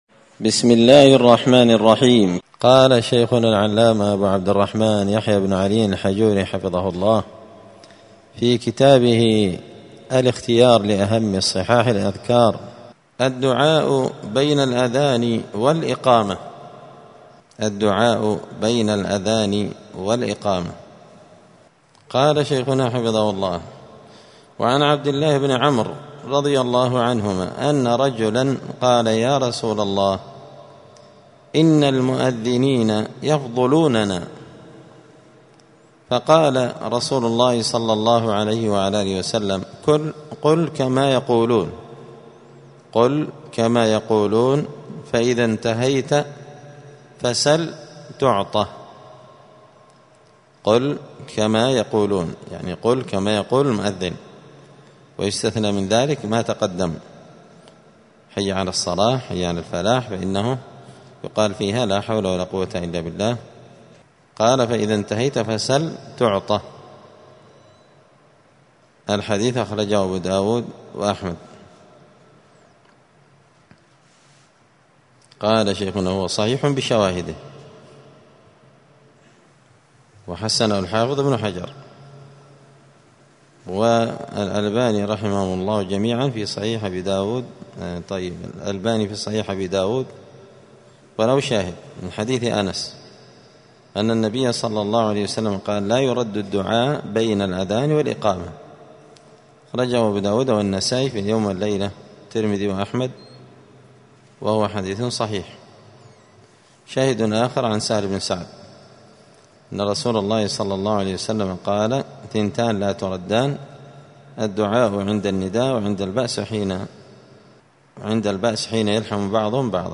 *{الدرس السادس (6) الدعاء بين الأذان والإقامة}*